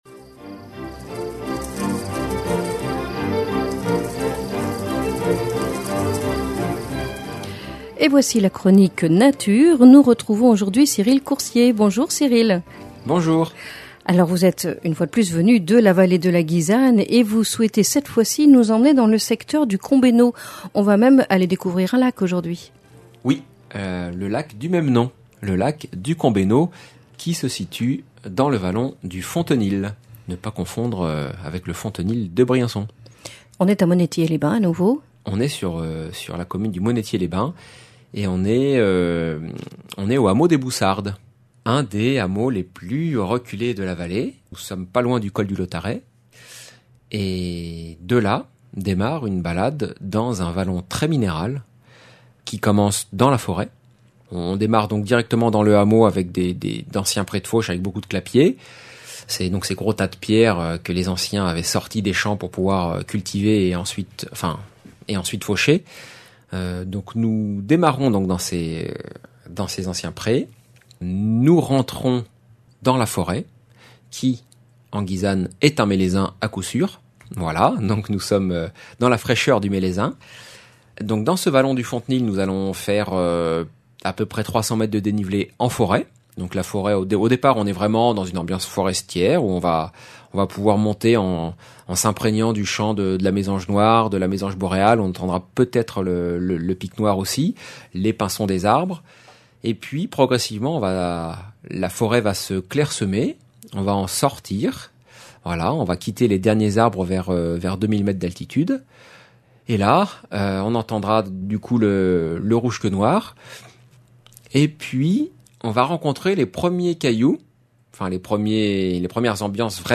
Chronique nature